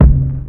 Sadboys Kick.wav